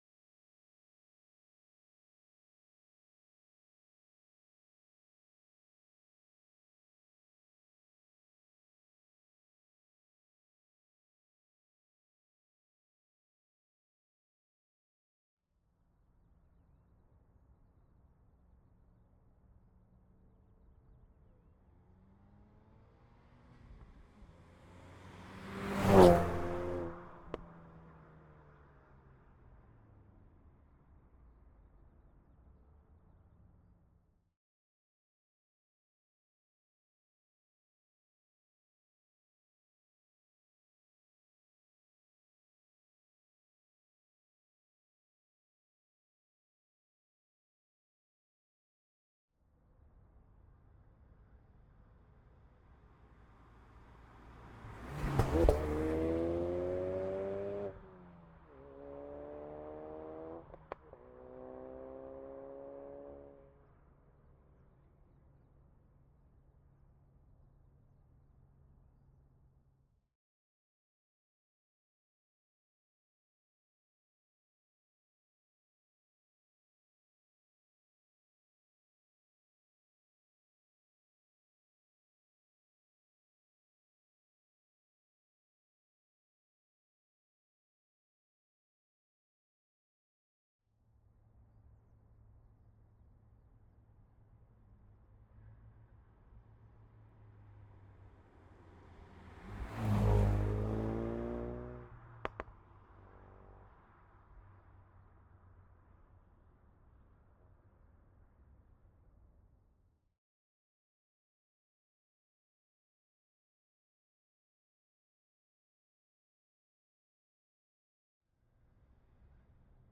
Ford_Focus_RS_t4_Ext_By_Gearshifts_ORTF_MKH8040.ogg